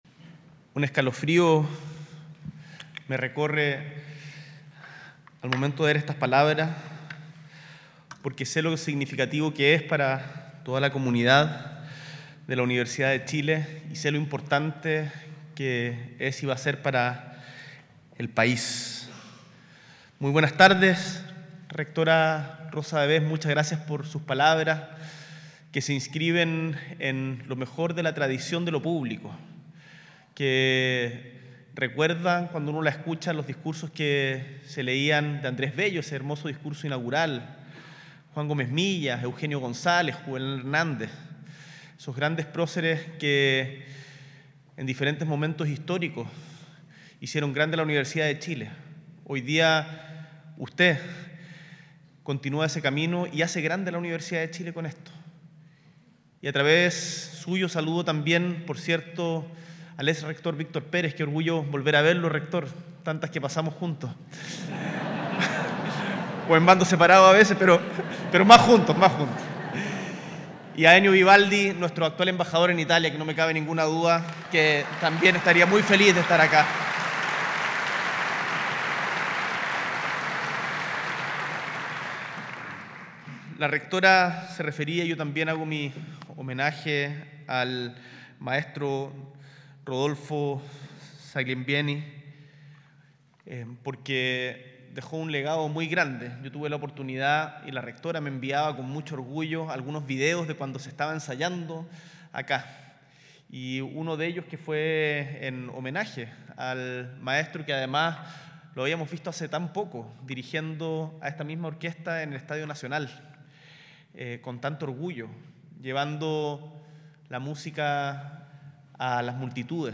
S.E. el Presidente de la República, Gabriel Boric Font, encabeza la inauguración de la Gran Sala Sinfónica de la Universidad de Chile.